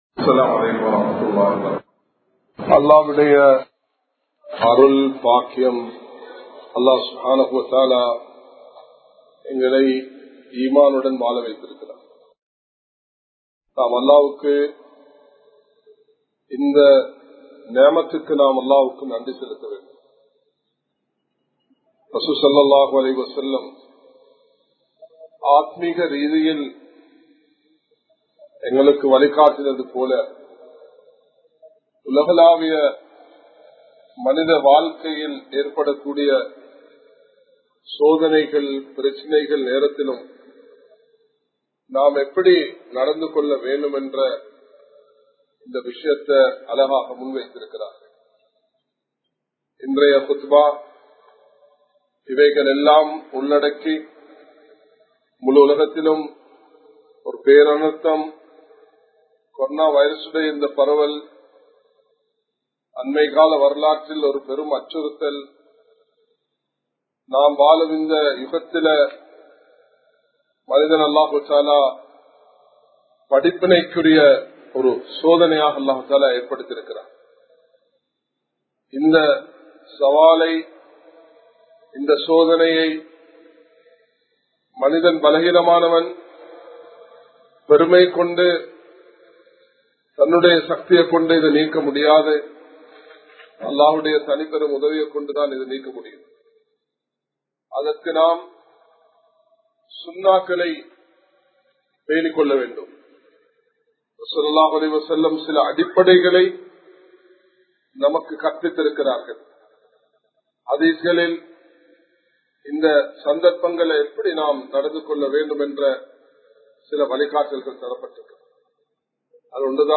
Bayans
Colombo 03, Kollupitty Jumua Masjith